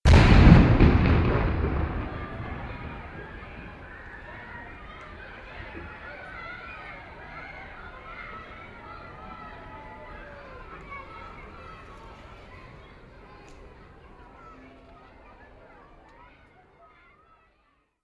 Impact